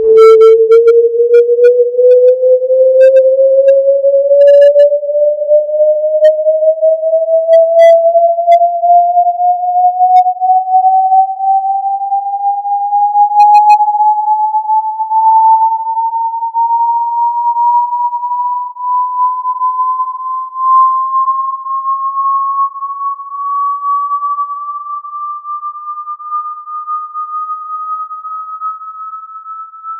whoosh sci fi
boom electric hard hit impact noise sci-fi scifi sound effect free sound royalty free Sound Effects